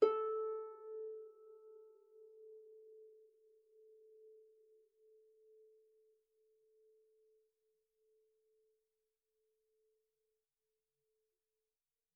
KSHarp_A4_mf.wav